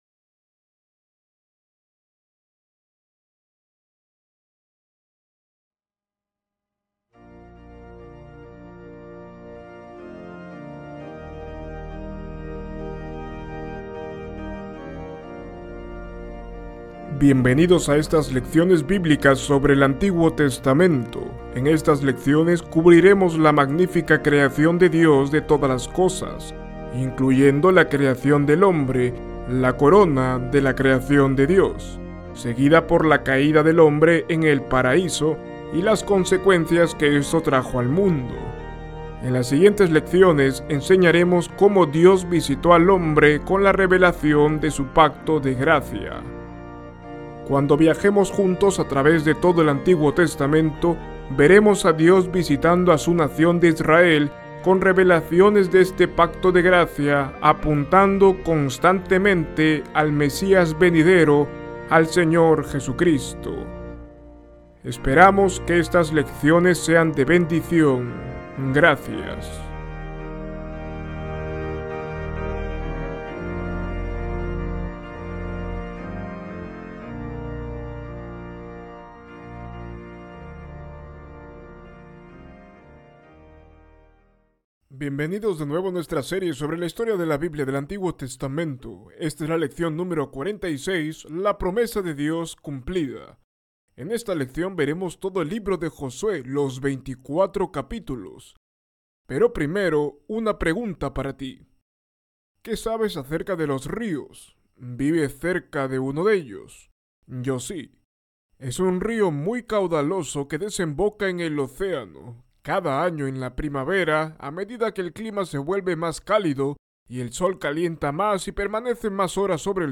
Esta lección ofrece un panorama general del libro de Josué, y la fidelidad de Dios al ingresar a Su pueblo a la tierra prometida de Canaán. Ver video Descargar video MP4 Escuchar lección Descargar audio en mp3 Ver transcripción en PDF Descargar transcripción en PDF Guia de Estudio